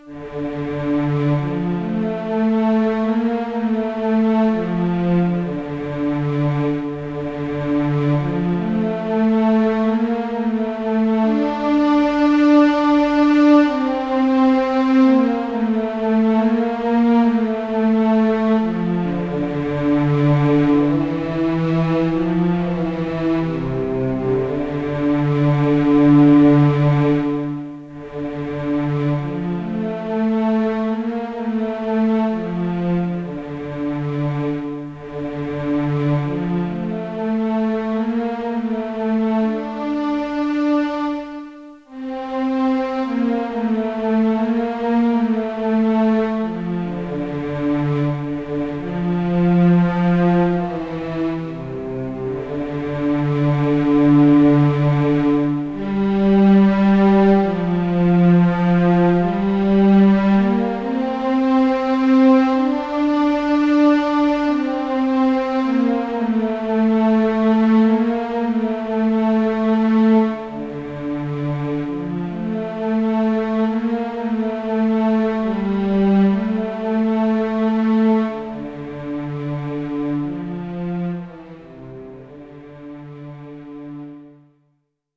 Schlichtere Version. Streichorchester.
Sentimentale Melodie, nachdenkliche Grundstimmung.